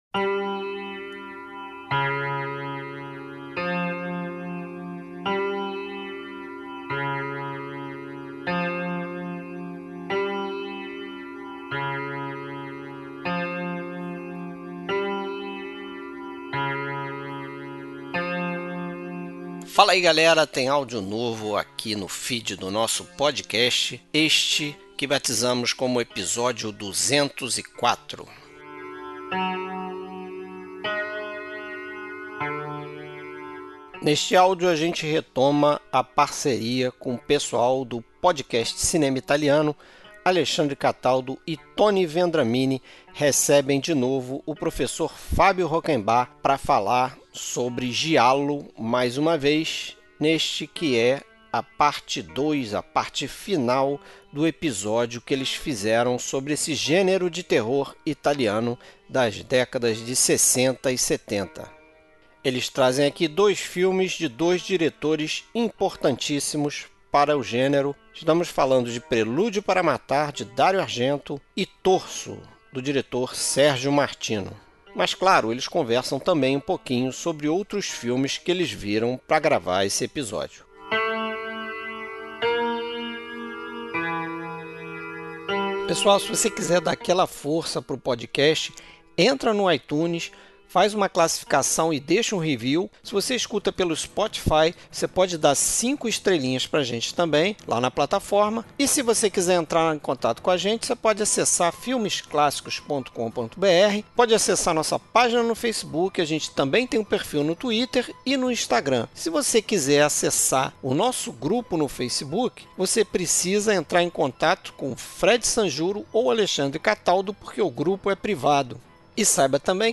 Trilha Sonora: Trilhas sonoras compostas para os filmes comentados.